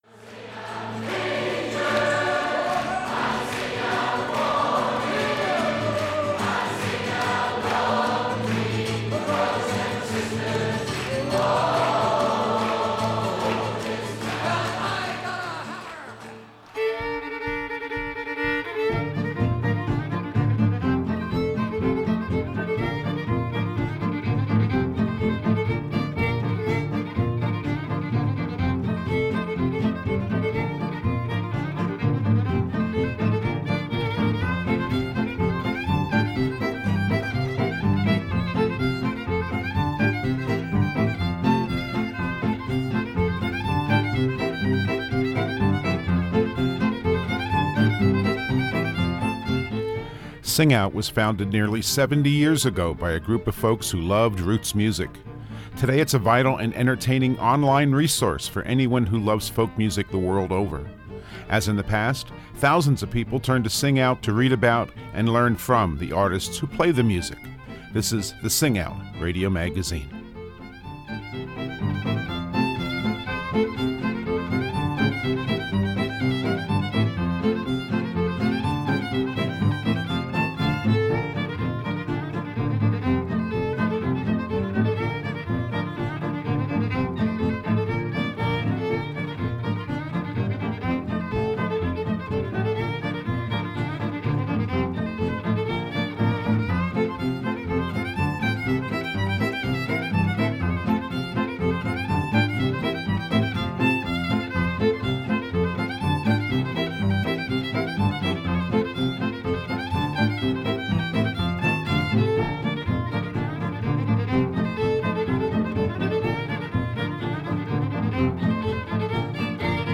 This week and next we'll feature musicians who have been inspired by music from their home region and are quite proud of it.